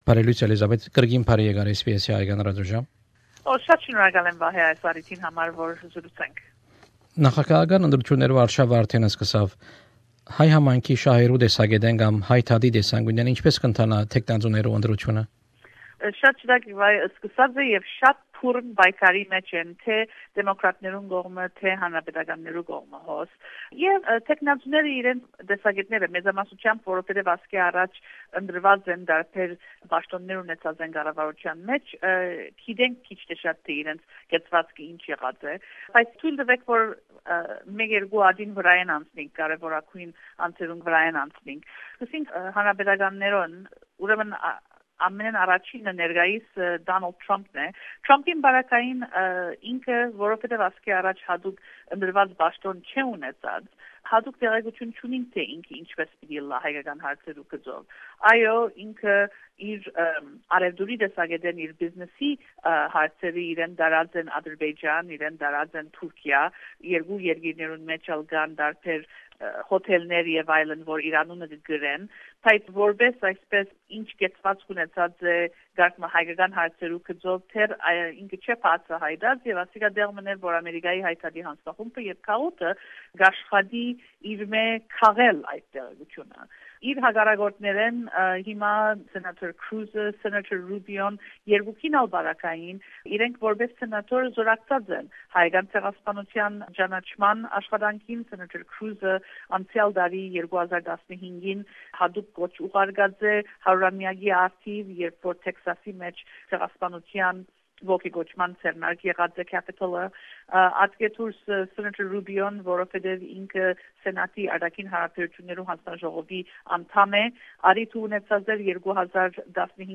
An interview with ANCA's